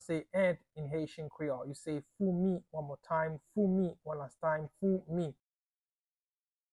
Pronunciation:
Listen to and watch “Foumi” audio pronunciation in Haitian Creole by a native Haitian  in the video below:
How-to-say-Ant-in-Haitian-Creole-Foumi-pronunciation-by-a-Haitian-teacher.mp3